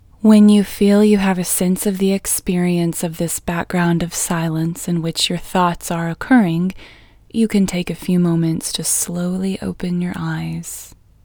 QUIETNESS Female English 15